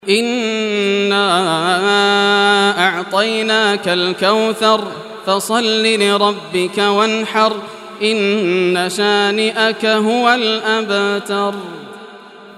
Surah Kausar Recitation by Yasser al Dosari
108-surah-kauthar.mp3